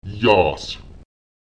Lautsprecher yás [jaùs] warm